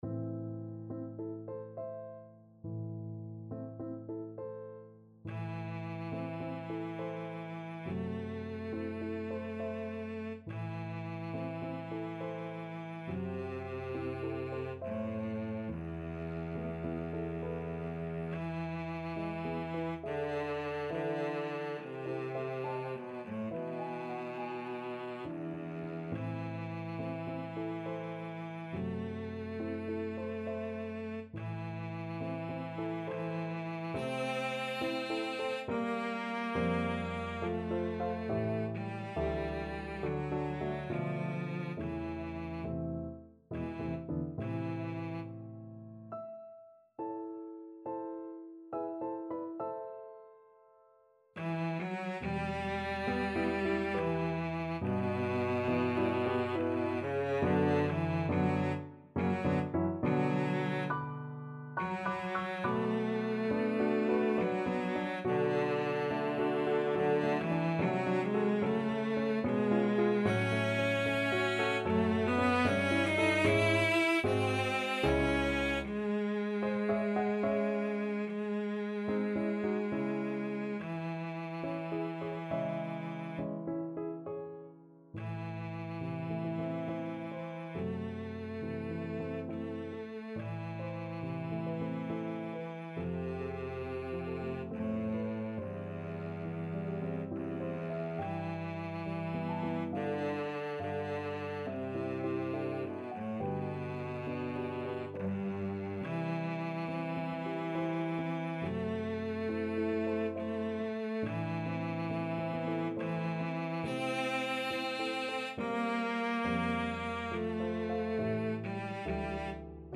Andante =69